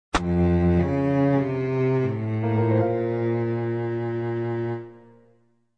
GameFail.mp3